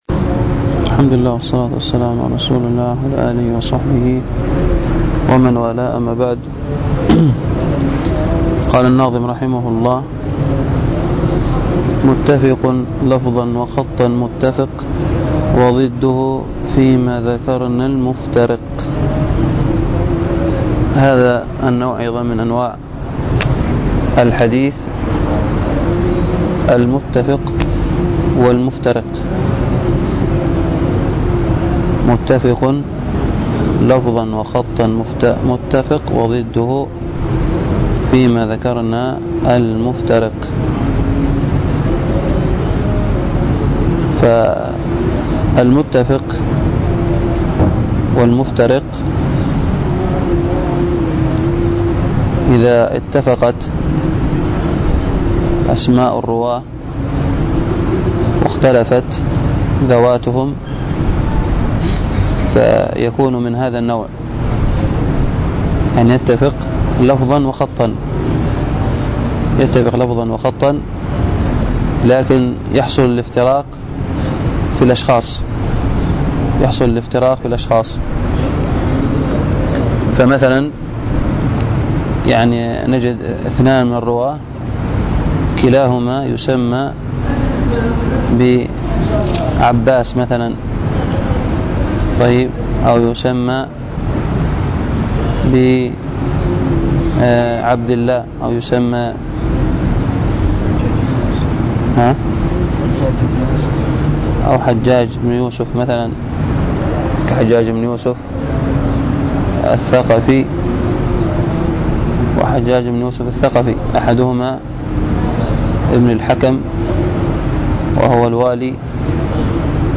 الدرس في شرح المنظومة البيقونية 10، ألقاها